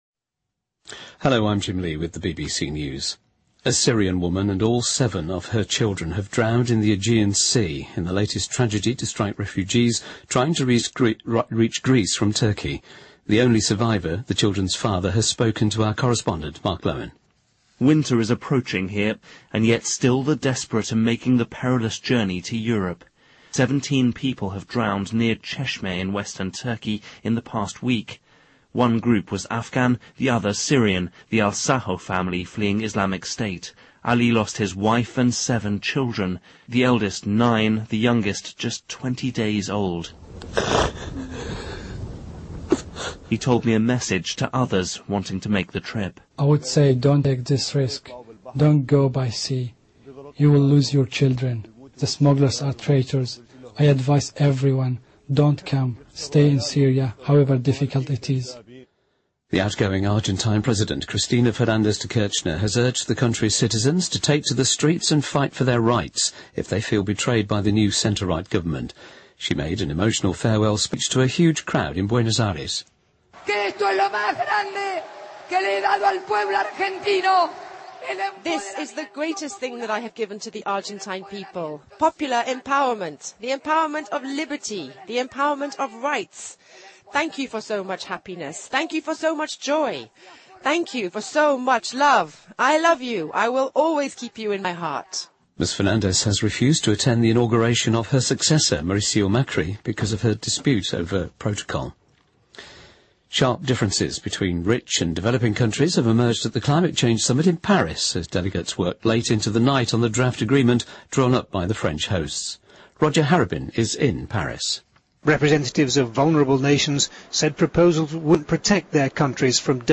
BBC news,2015-12-12新闻